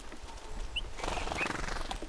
tuollainen korkeampi punatulkun ääni
tuollainen_korkeampi_punatulkun_aani.mp3